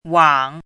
怎么读
wǎng
wang3.mp3